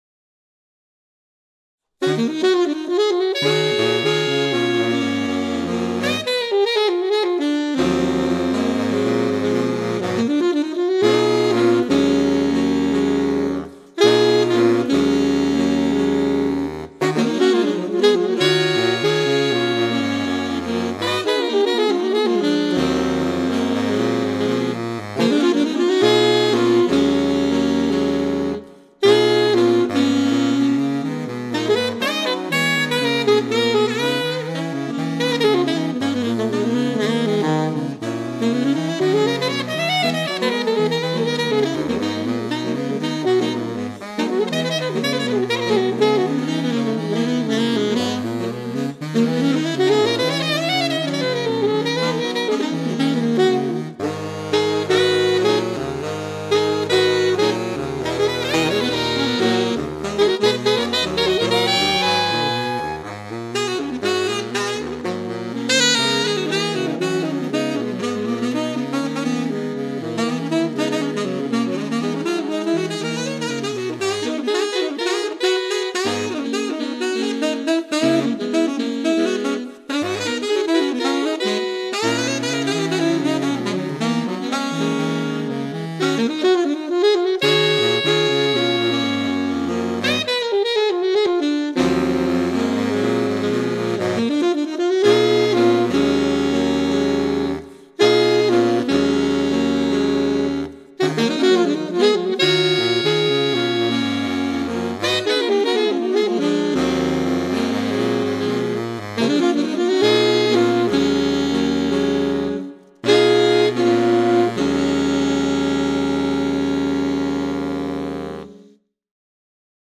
Four in One Saxophone Quartet
Four in One is a Saxophone Quartet made up of four definitely different but like-minded players who have been meeting to play original quartet arrangements in my home at times when gathering in other groups has not been possible.